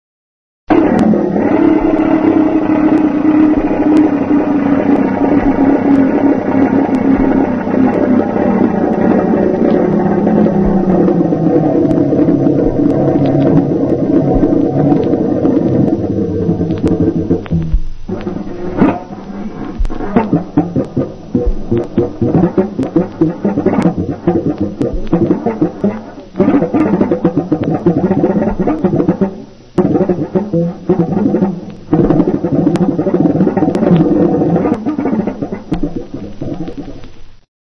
Gracidatore esempio sonoro
gracidatore_russolo.mp3